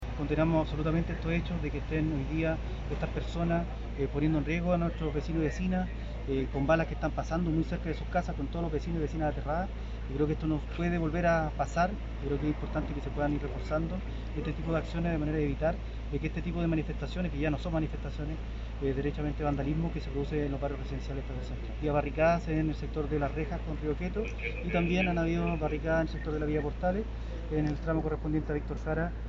Dentro de la comuna de Estación Central, existió la preocupación por la utilización de armas de fuego, algo que fue criticado duramente por el alcalde Felipe Muñoz.